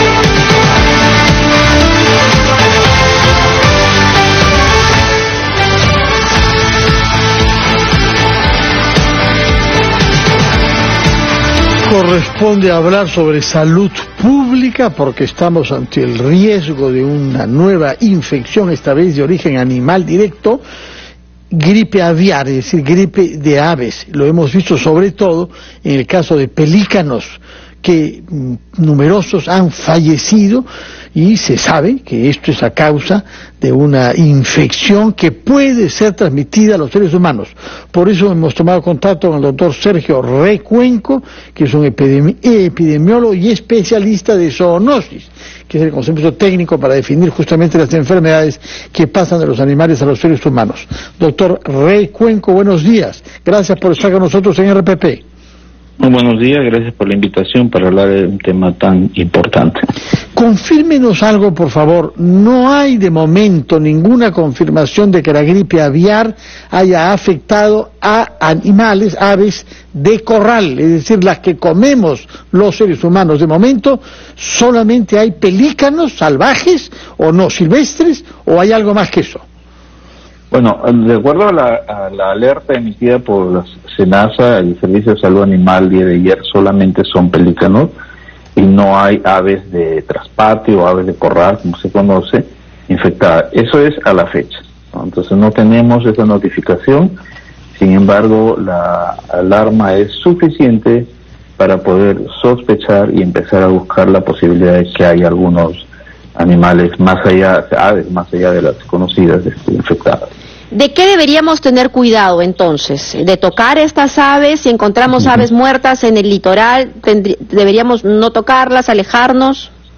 Entrevista al médico epidemiólogo